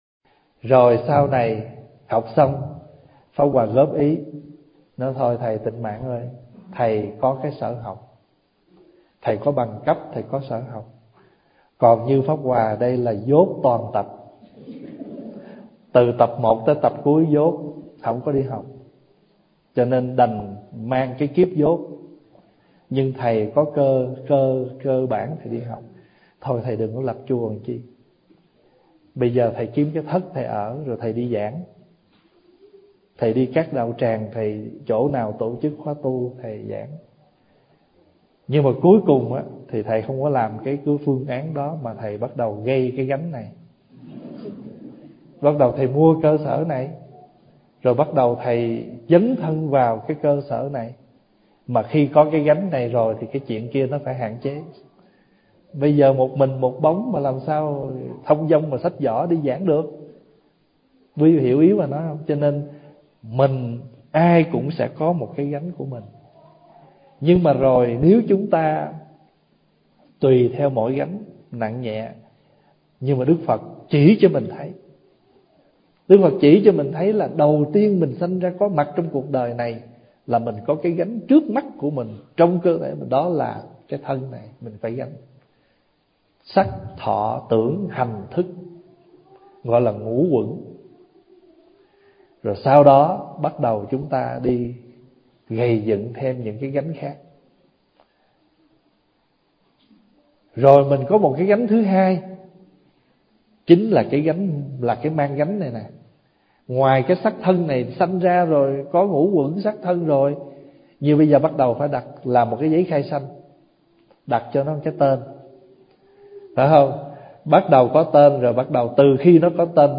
– Bài Giảng 60 Tuổi Mà Không Bỏ Được 5 Điều Này Rất Khó Có Bình An.